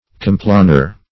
Complanar \Com*pla"nar\, a.